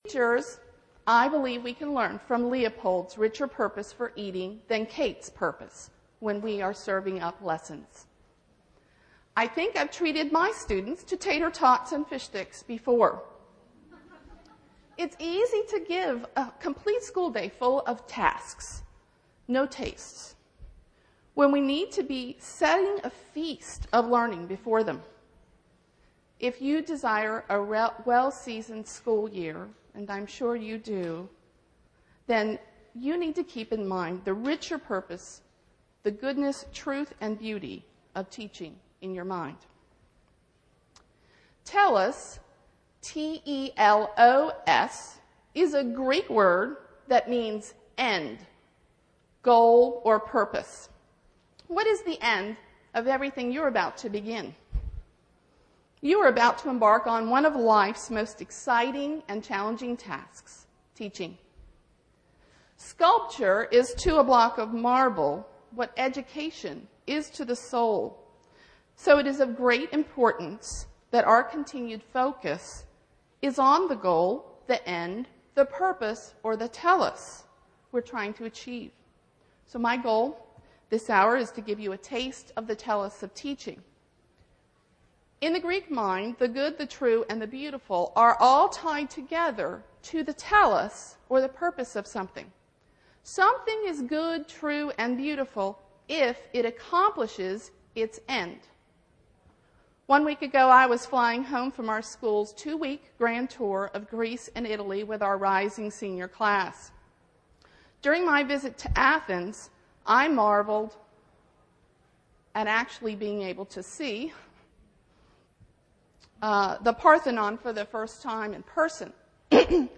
2008 Workshop Talk | 0:42:47 | K-6
The Association of Classical & Christian Schools presents Repairing the Ruins, the ACCS annual conference, copyright ACCS.